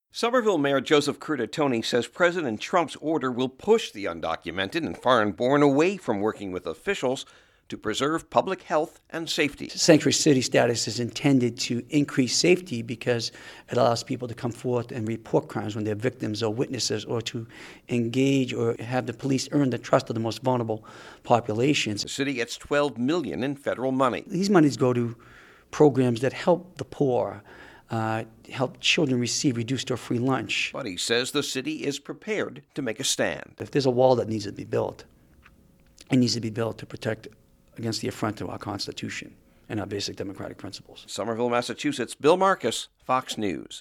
Joseph A. Curtatone, Mayor of Somerville, Massachusetts, a sanctuary city and home to 80,000 residents a third of whom, he says, are foreign-born.